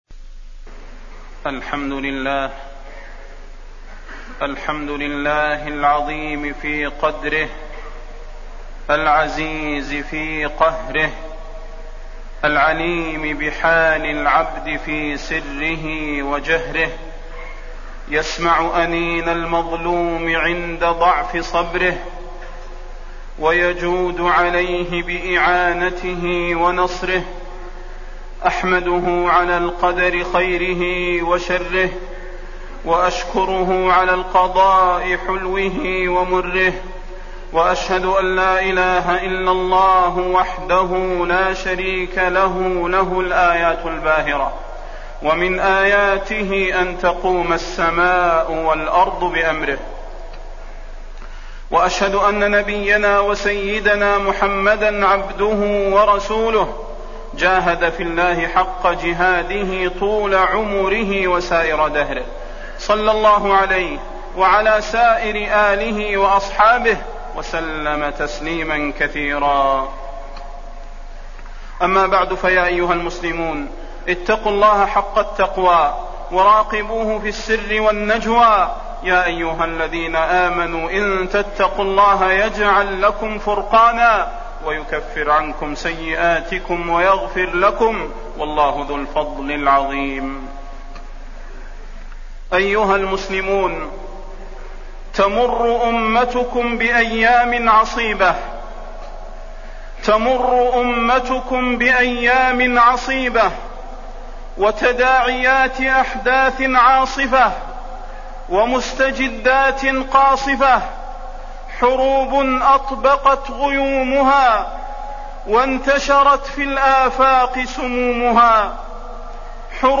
فضيلة الشيخ د. صلاح بن محمد البدير
تاريخ النشر ٥ محرم ١٤٣٠ هـ المكان: المسجد النبوي الشيخ: فضيلة الشيخ د. صلاح بن محمد البدير فضيلة الشيخ د. صلاح بن محمد البدير انقذوا غزة